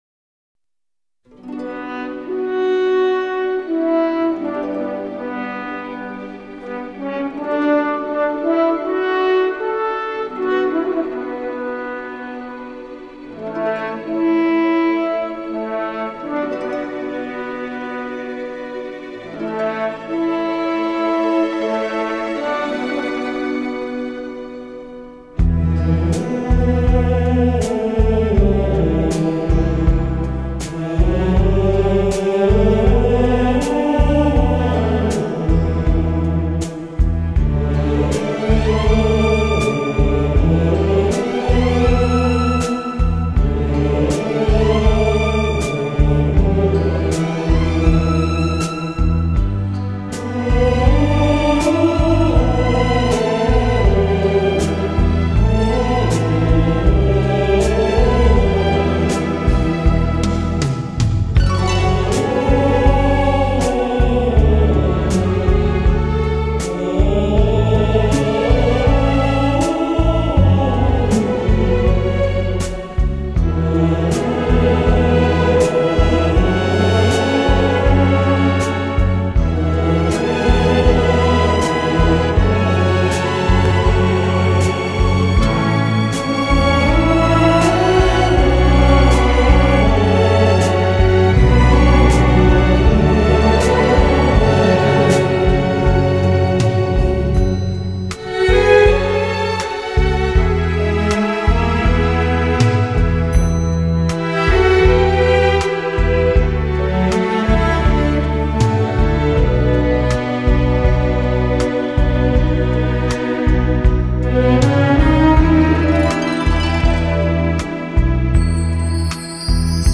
很恢弘的一首曲子